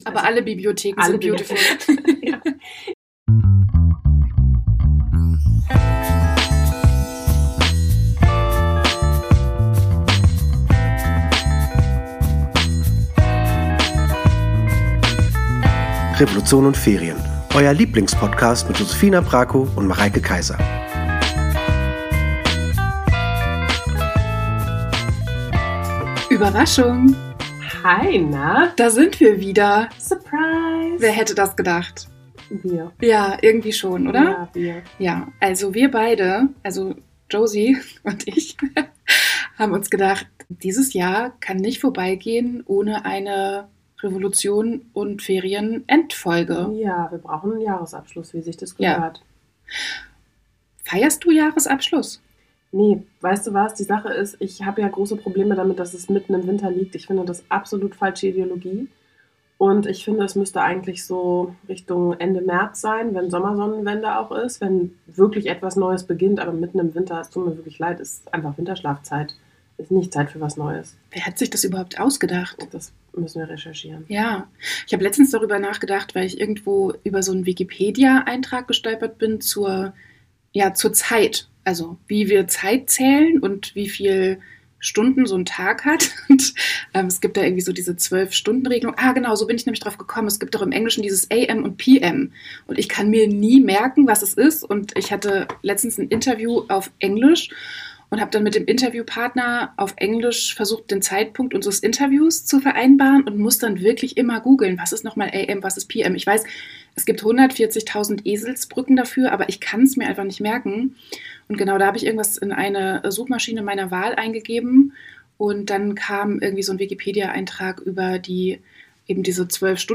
Wir hoffen, euer Jahr war besser als unser Ton in dieser Folge.